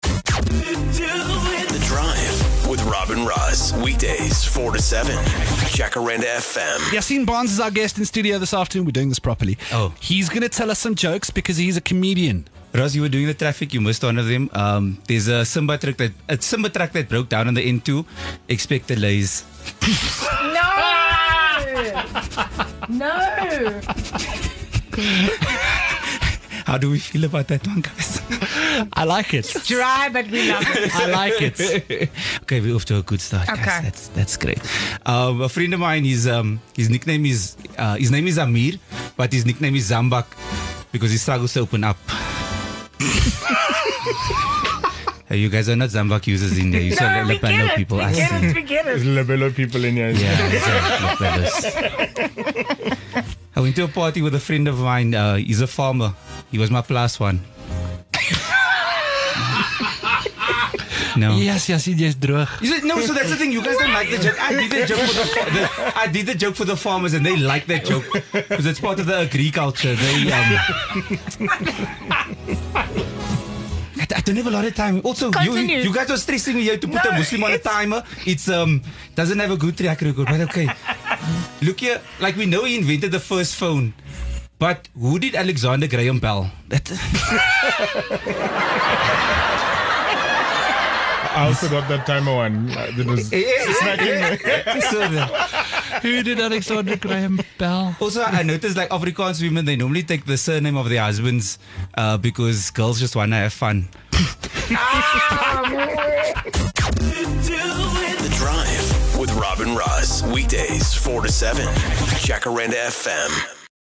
He stopped by. the studio and had us rolling with laughter so take a listen.